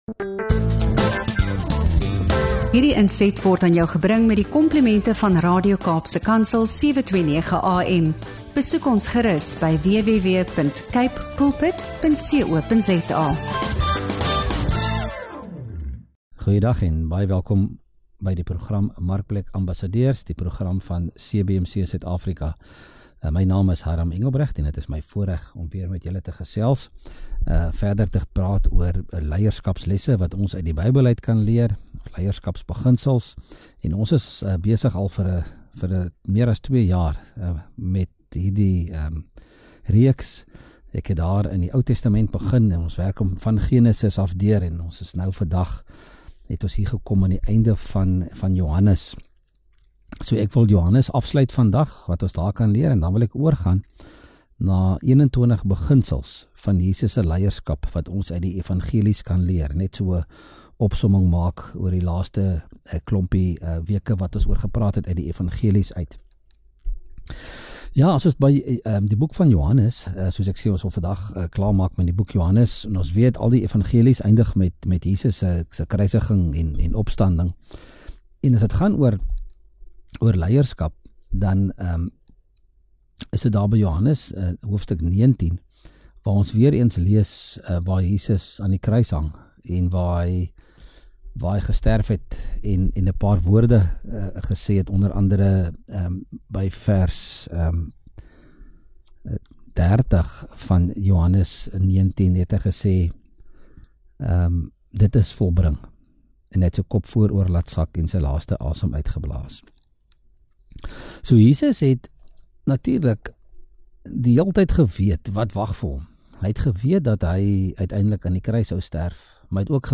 Hy verduidelik hoe Christus die voorbeeld van diensbare leierskap stel, en deel praktiese stappe oor hoe ons ander kan mentor, bemagtig en voorberei om die werk van die Koninkryk voort te sit. ŉ Inspirerende gesprek oor geloof, leierskap en geestelike vermenigvuldiging.